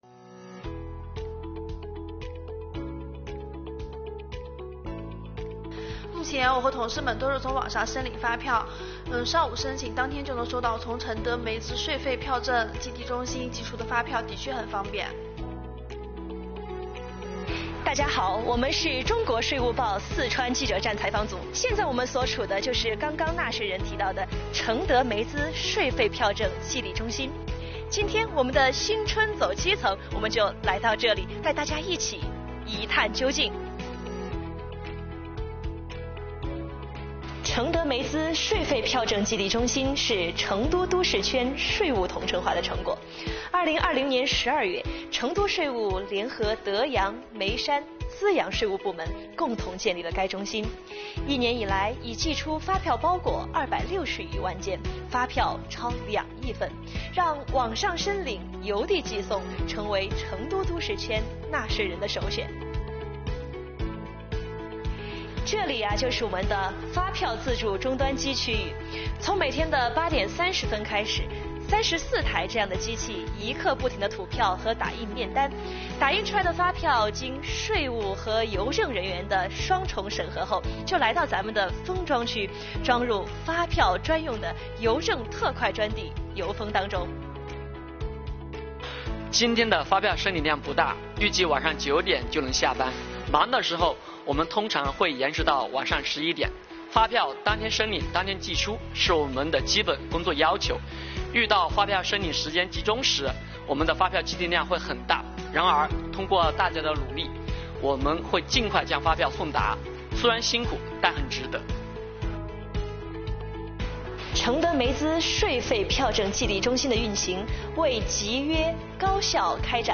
走进位于成都主城区的成德眉资税费票证寄递中心，记者看到的是一片繁忙的景象。
34台发票自助终端机工作着，不停打印面单和吐出发票，声音此起彼伏，像在演奏税务交响曲。
随着一阵轰隆轰隆的声音，手推车将发票包裹送进绿色的邮政封闭货车。